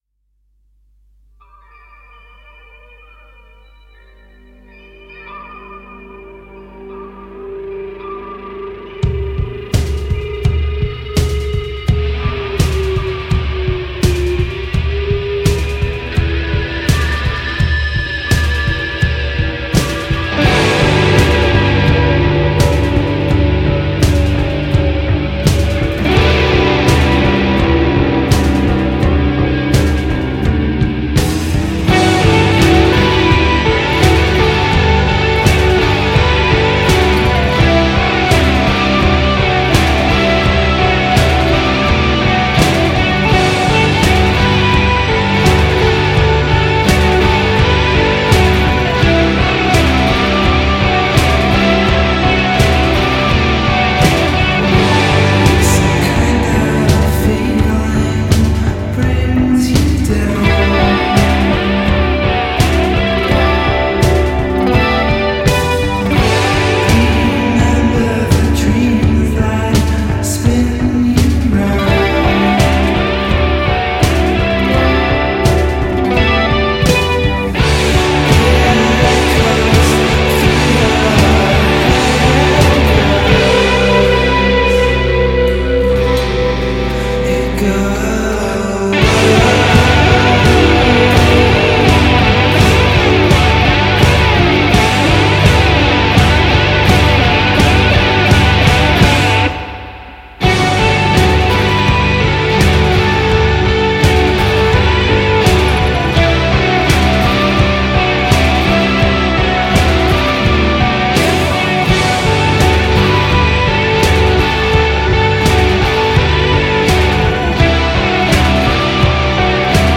Leeds, UK quintet
shoegaze outfit
vocals, guitar